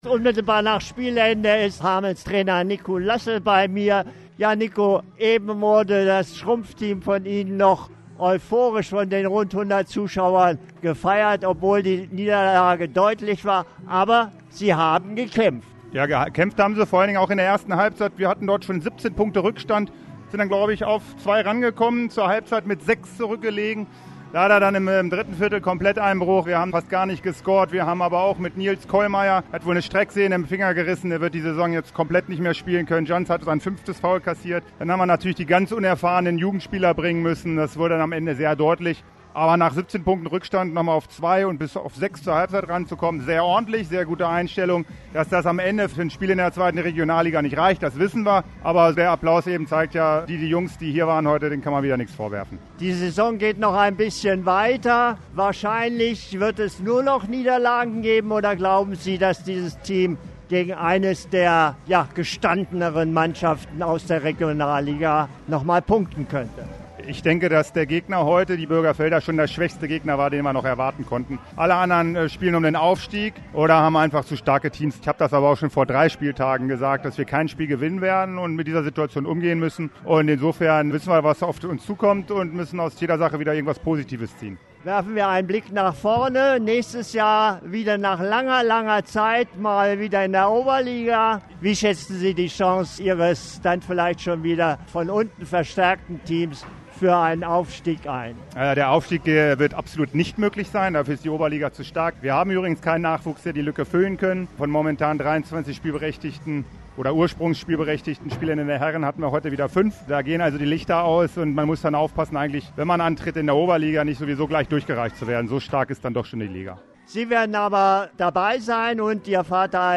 Interview nach dem Spiel